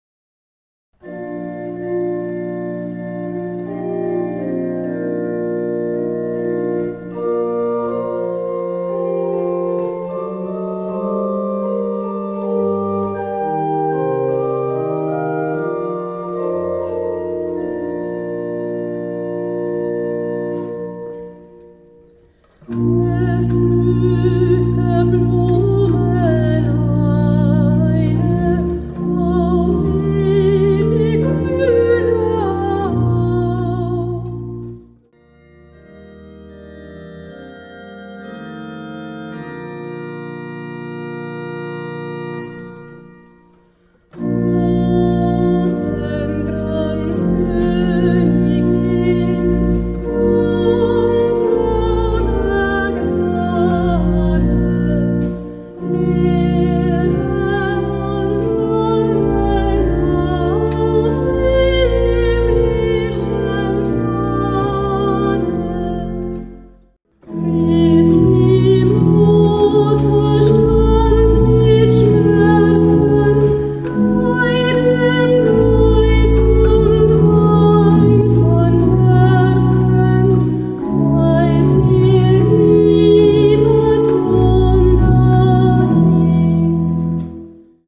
• Ausführung: mit Orgelbegleitung